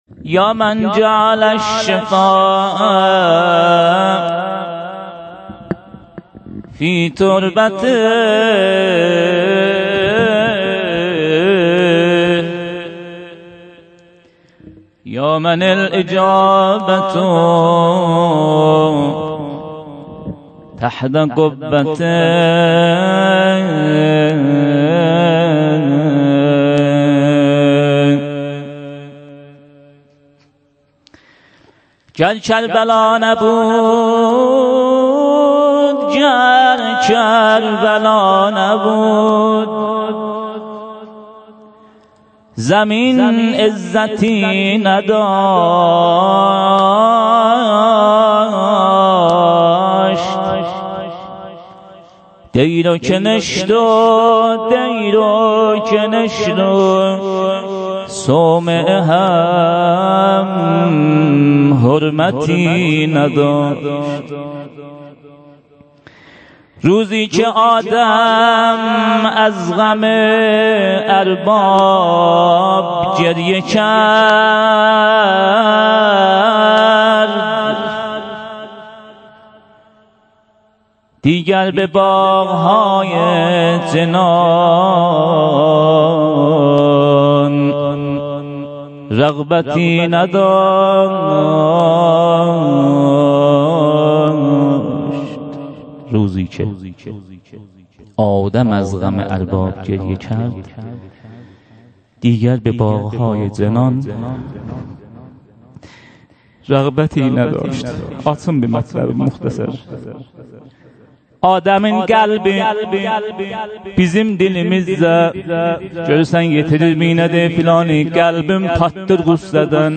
هیأت محبان اهل بیت علیهم السلام چایپاره